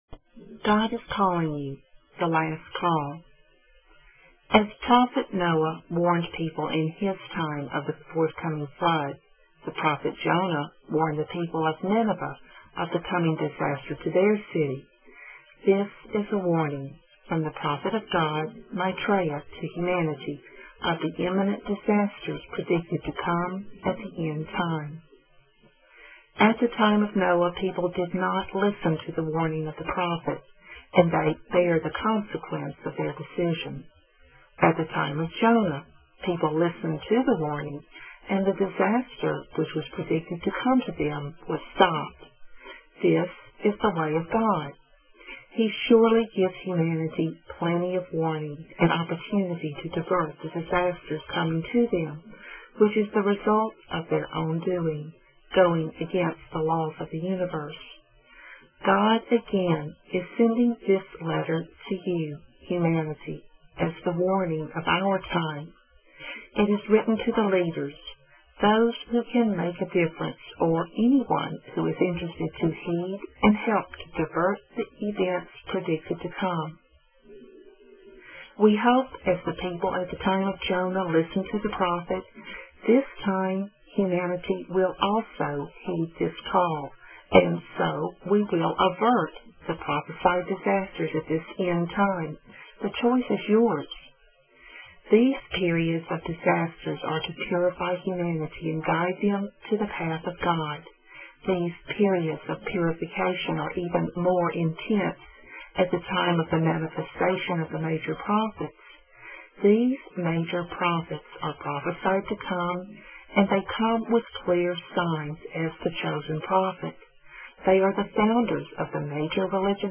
God Is Calling You (The Last Call) Audio: Male - Female